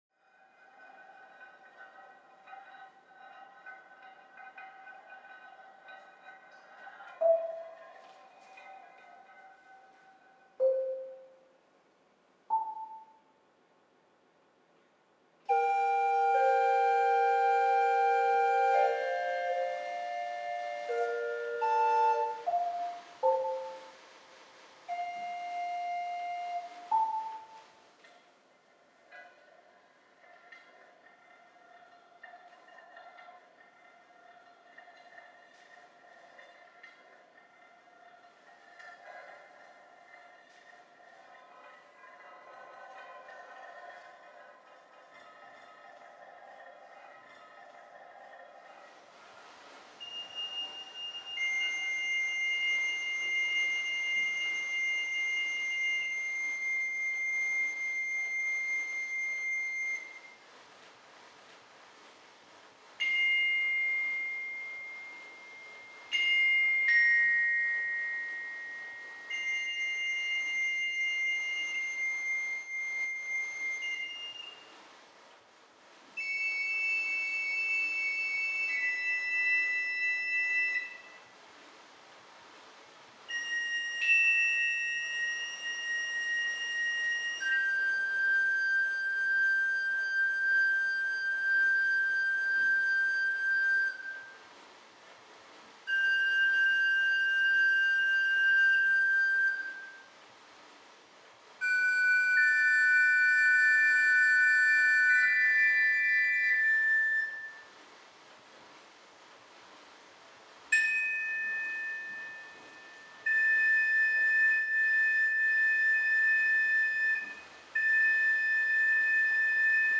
Schlagzeug
Drehorgel